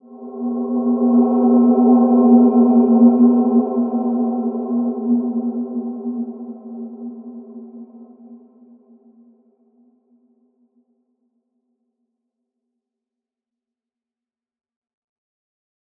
Wide-Dimension-B2-f.wav